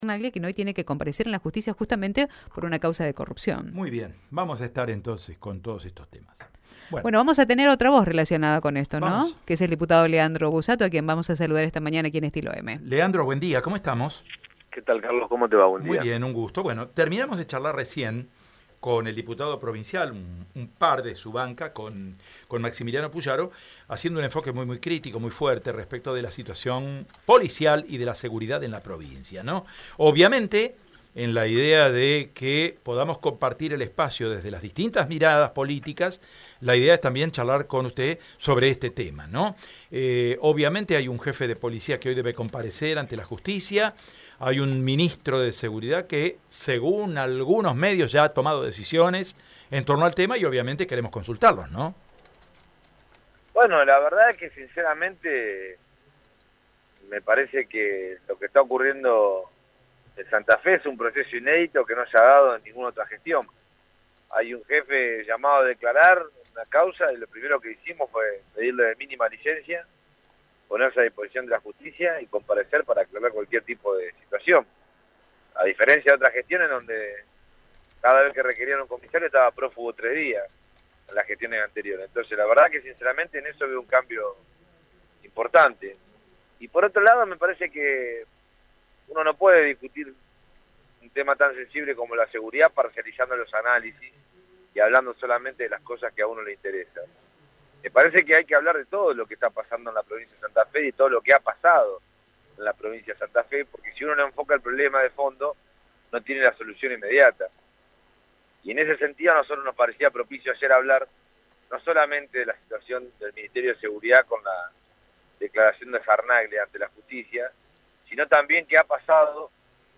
Este jueves durante la sesión en la Cámara de Diputados, el exministro de Seguridad de la provincia, Maximiliano Pullaro, estalló en contra de la gestión actual de las fuerzas de seguridad. El diputado oficialista, Leandro Busatto, no demoró en responder.